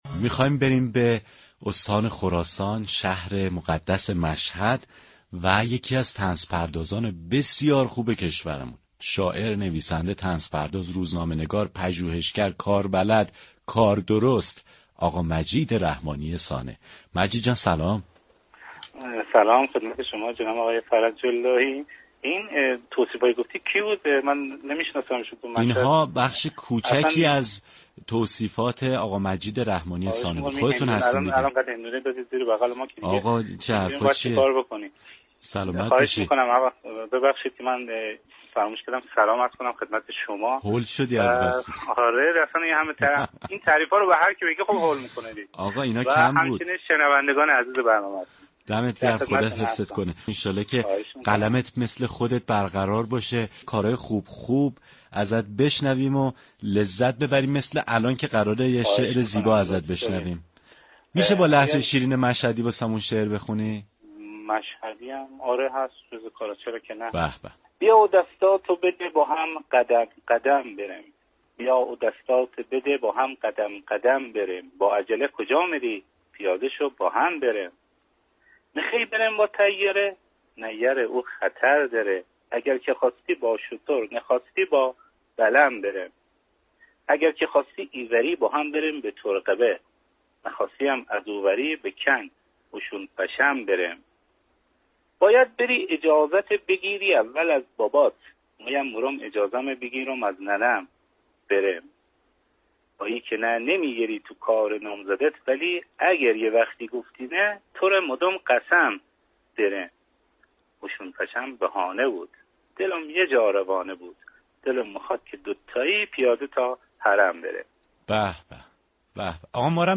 اثری طنز از او با خوانش خود شاعر بشنوید.